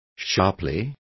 Also find out how nitidamente is pronounced correctly.